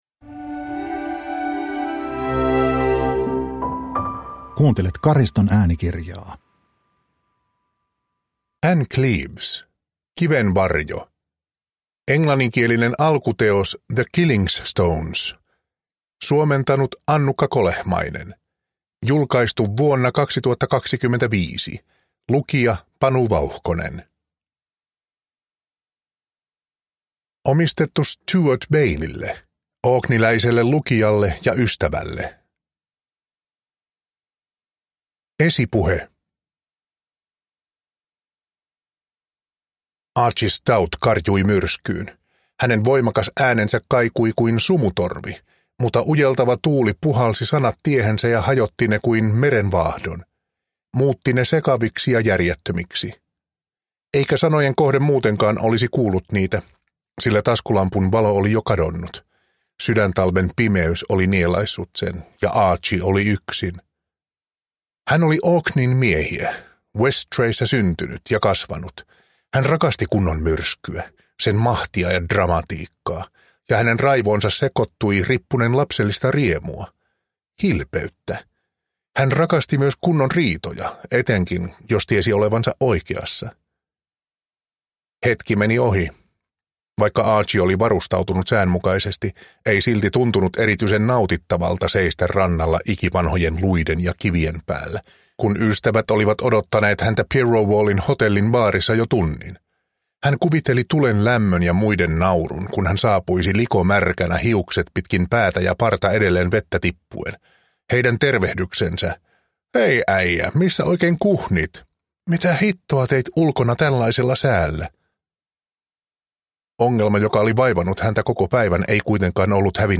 Kiven varjo (ljudbok) av Ann Cleeves